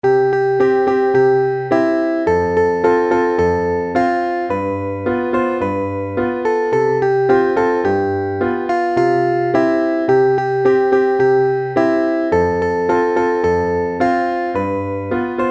Partitura para piano, voz y guitarra.